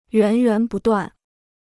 源源不断 (yuán yuán bù duàn) Free Chinese Dictionary